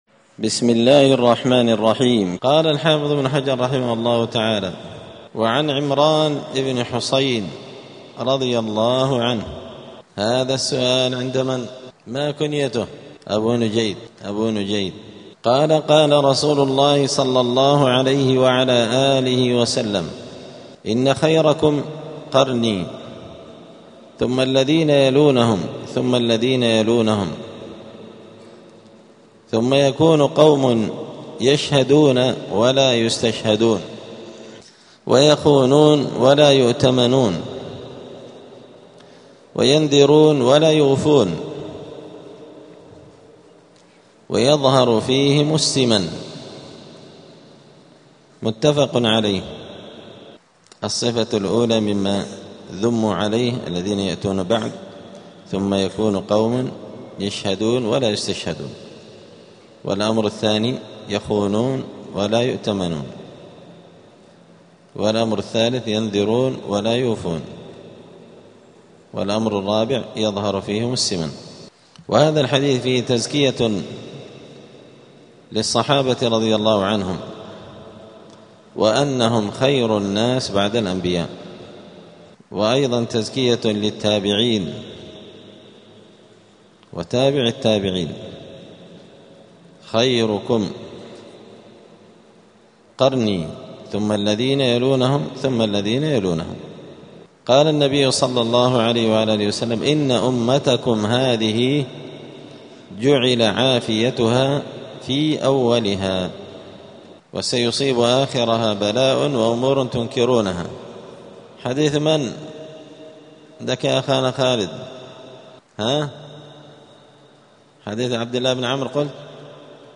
*الدرس الخامس عشر (15) {خير القرون}*